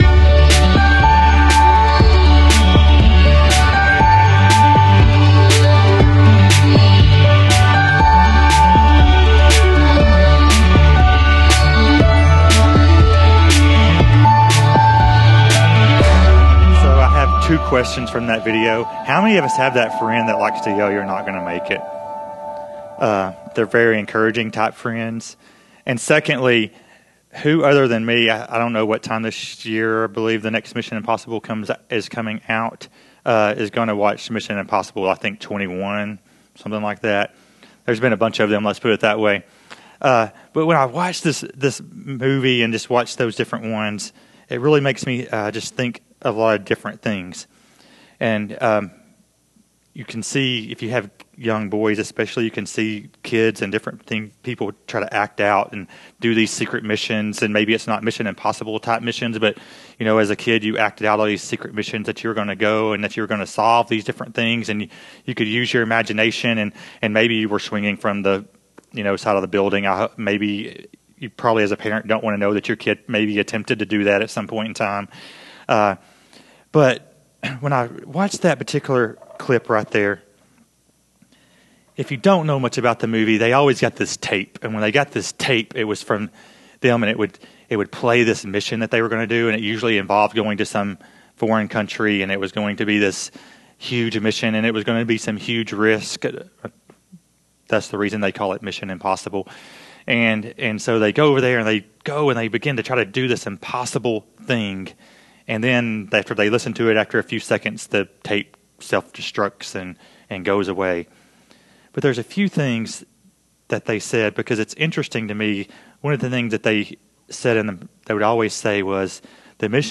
In this sermon series we are going to look at topics like how to connect with unbelievers, how serving others can open their hearts to the gospel, and how paying attention to our own spiritual growth can strengthen our witness.